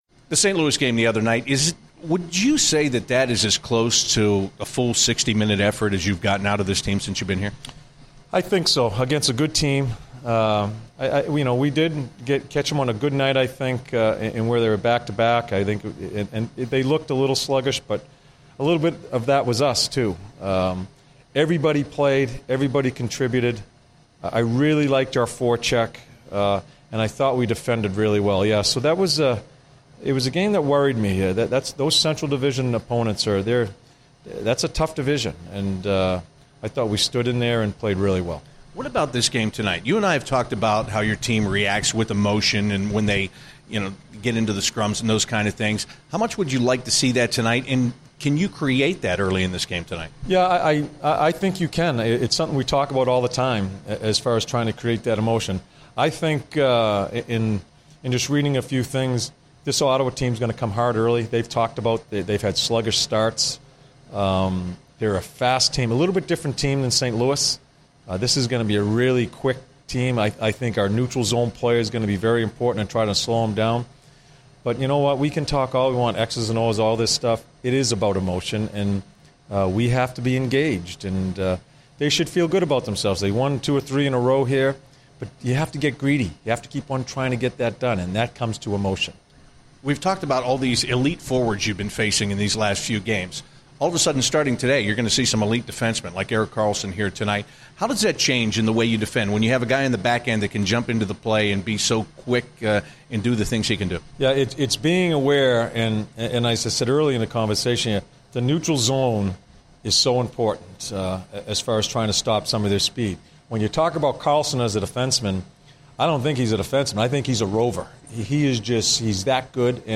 John Tortorella Pre-Game 11/19/15